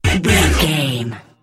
Aeolian/Minor
E♭
synthesiser
90s